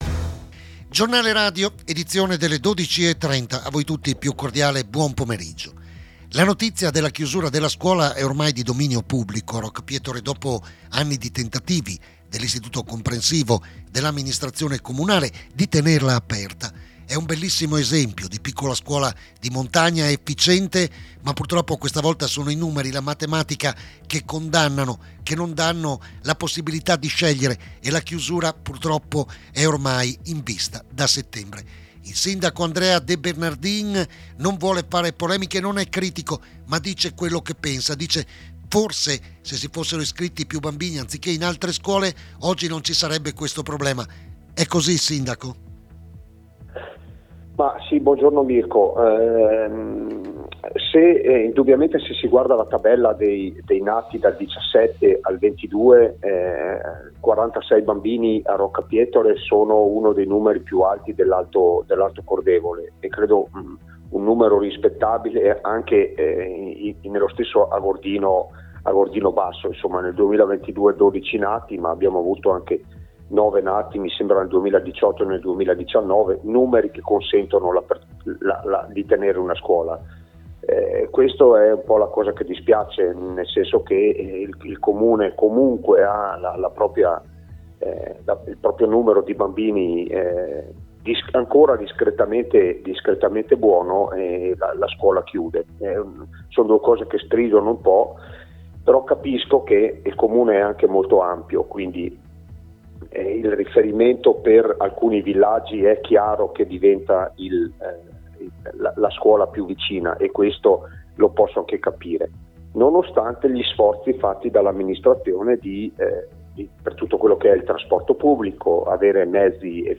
Ma ci sono colpe e colpe, da una parte l’andamento demografico, dall’altra le scelte di iscrivere comunque i propri figli in altri plessi scolastici. Ne abbiamo parlato con il sindaco, Andrea De Bernardin